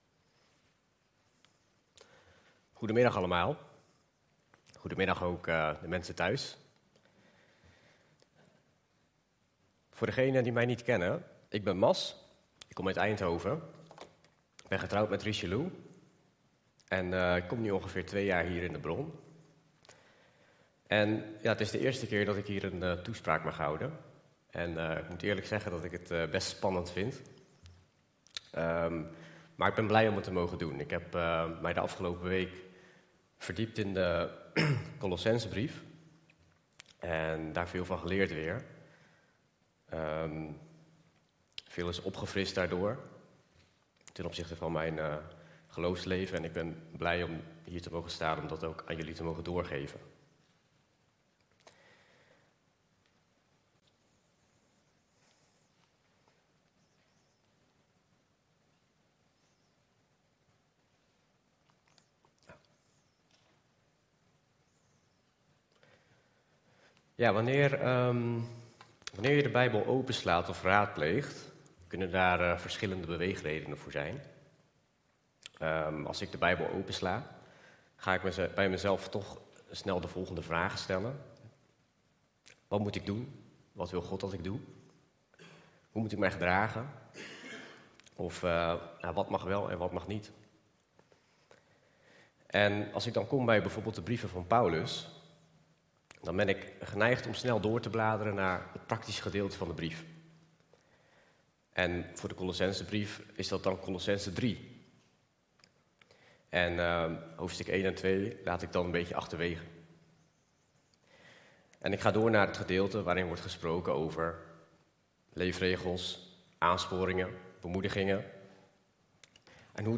Toespraak van 28 maart: Met Christus opgewekt - De Bron Eindhoven